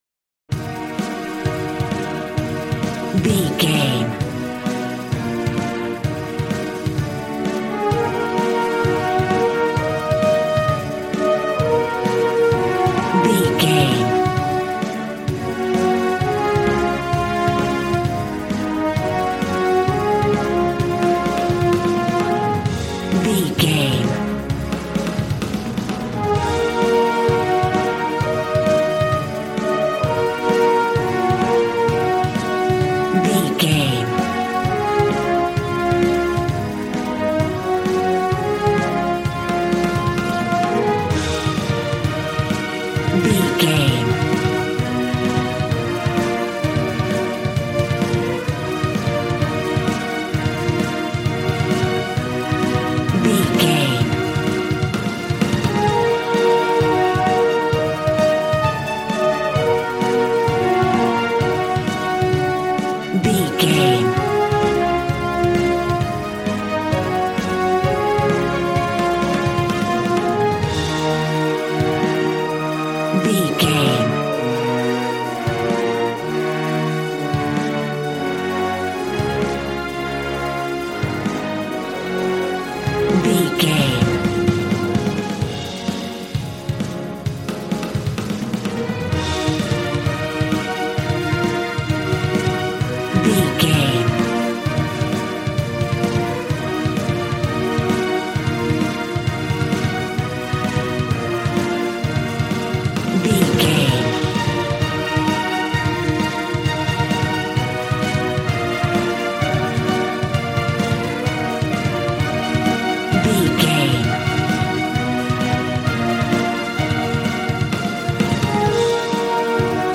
Aeolian/Minor
E♭
dramatic
epic
strings
violin
brass